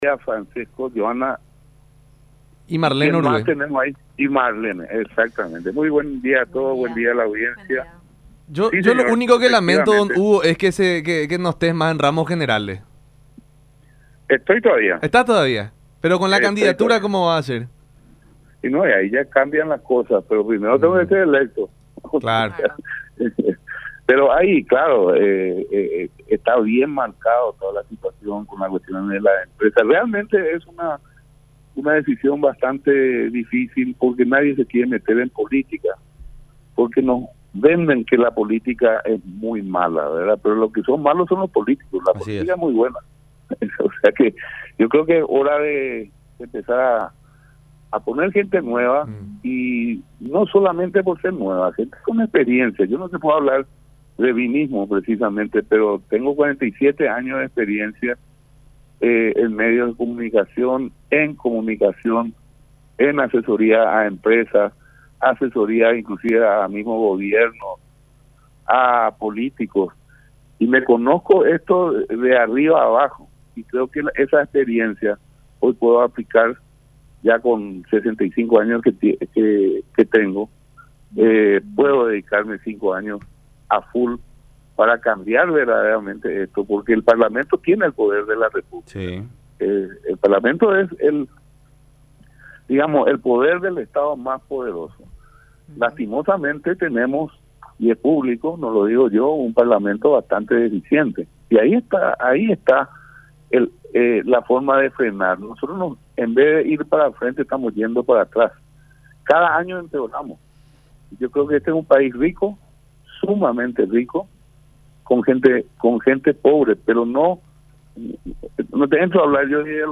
en diálogo con La Unión Hace La Fuerza por Unión TV